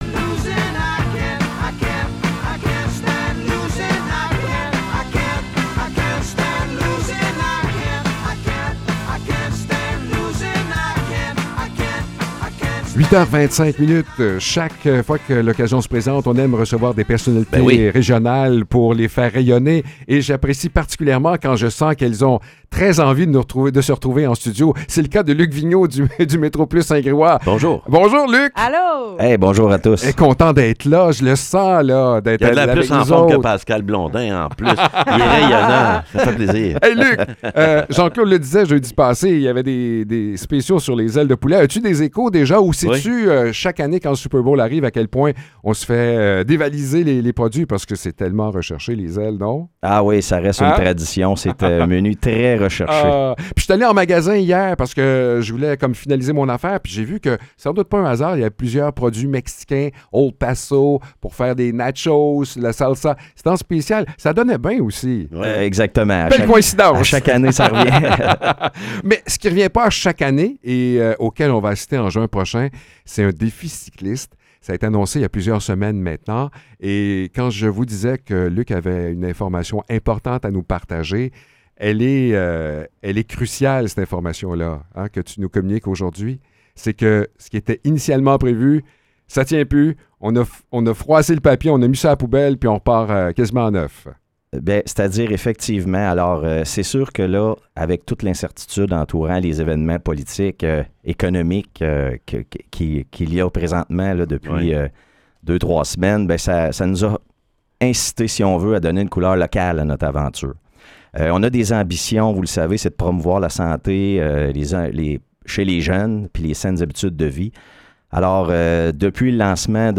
Entrevue pour le Défi Desjardins Nicolet-Bécancour